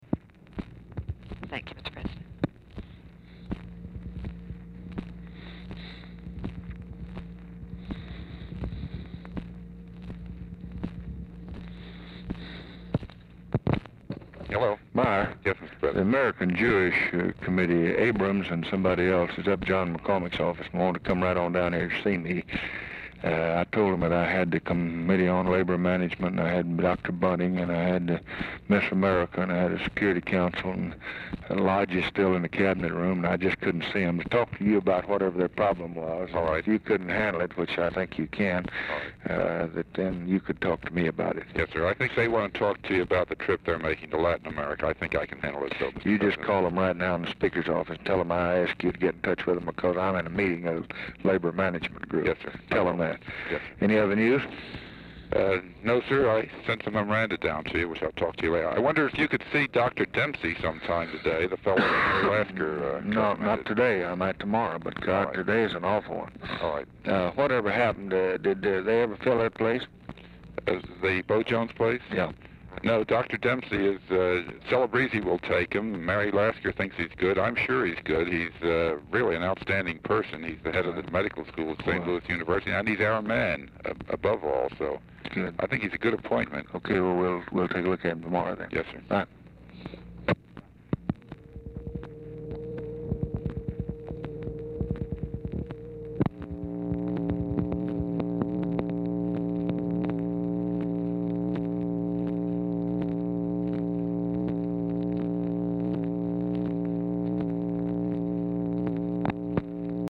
Telephone conversation # 3935, sound recording, LBJ and MYER FELDMAN, 6/29/1964, 11:54AM | Discover LBJ
Format Dictation belt
Location Of Speaker 1 Oval Office or unknown location